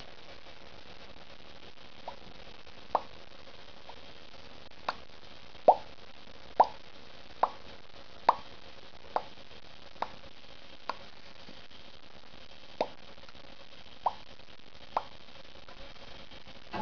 Stupid Sounds
drop.wav